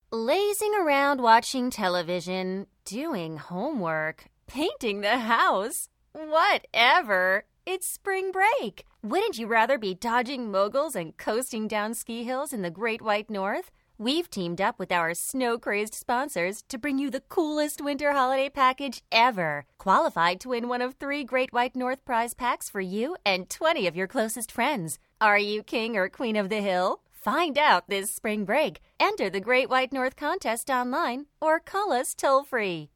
Radio Commercials
Woman 20'S - Lively, Fun Upbeat